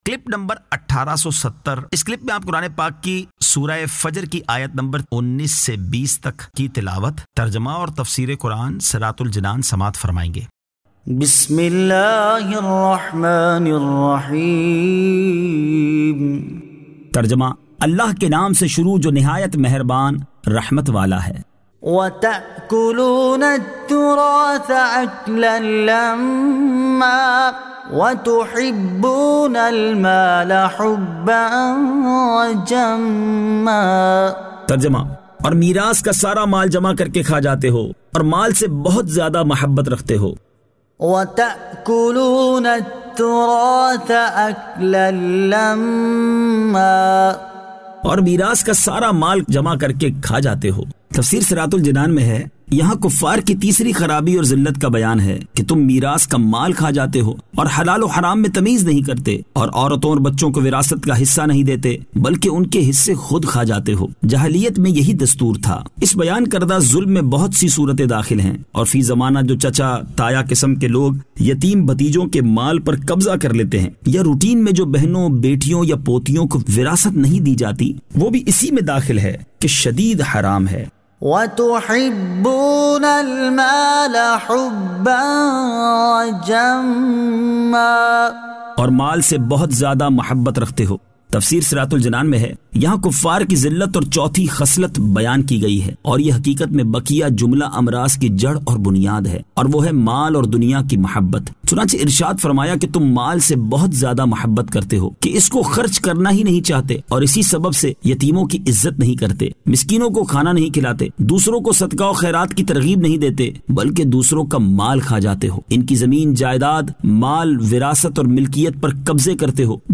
Surah Al-Fajr 19 To 20 Tilawat , Tarjama , Tafseer